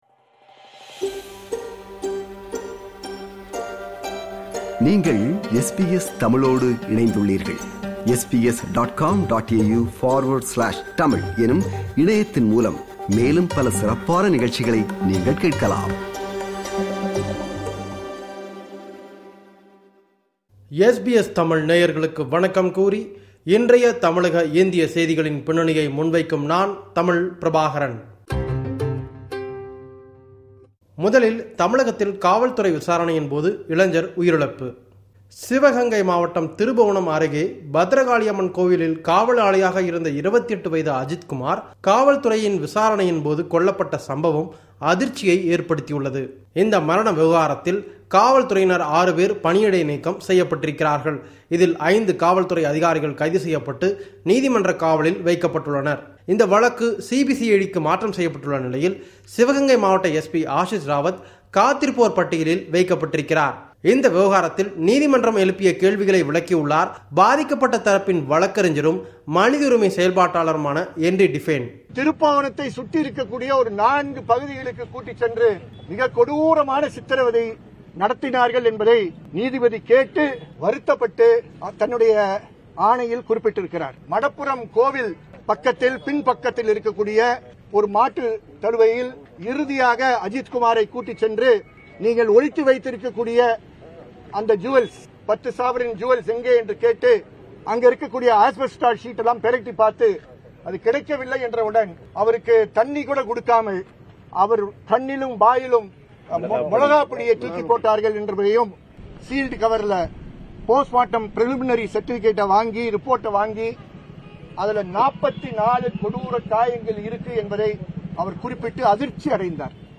செய்திகளின் பின்னணியை முன்வைக்கிறார் நமது தமிழக செய்தியாளர்